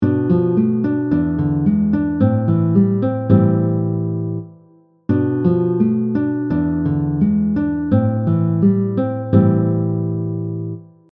You’ll hear the regular tempo and a slower tempo to help you learn each example.
Progression 1 chords are B half diminished, E 7, and A minor 7. This is would labeled as a “2 5 1” in the key of A minor.
Minor-7-b5-chord-progression-example-1.mp3